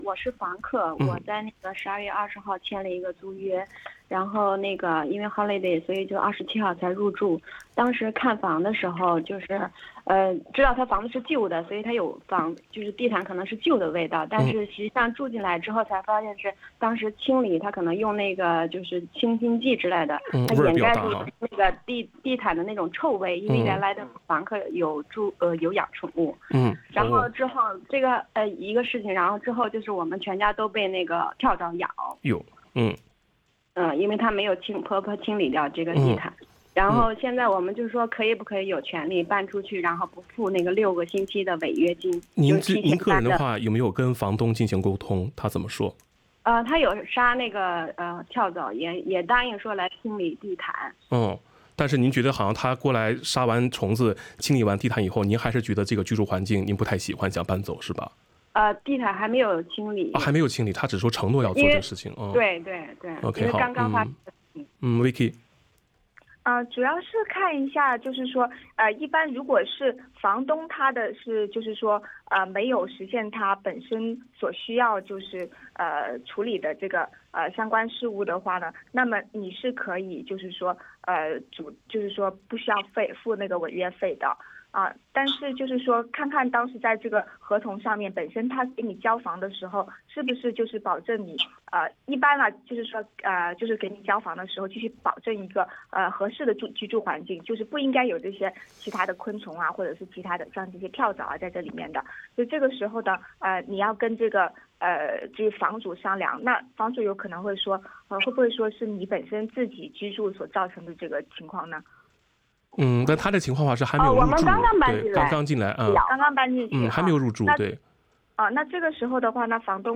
《消费者权益讲座》听众热线逢每月第二个周一上午8点30分至9点播出